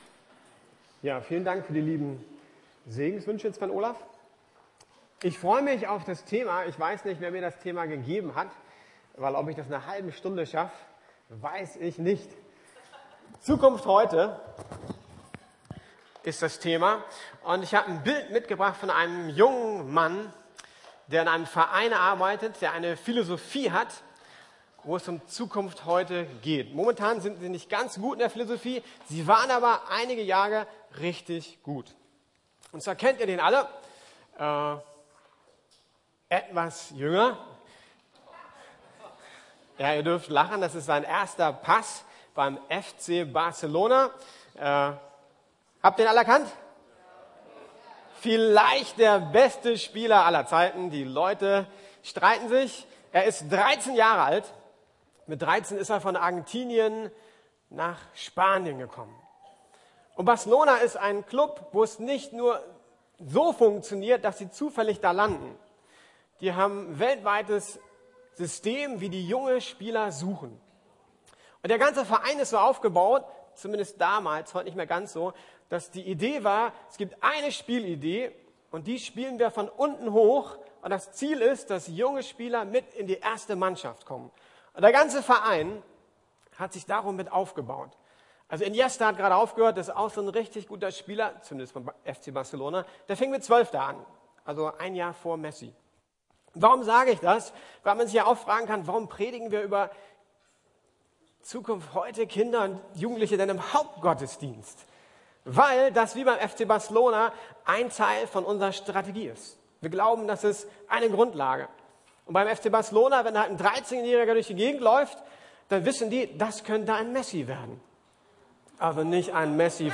Zukunft heute ~ Predigten der LUKAS GEMEINDE Podcast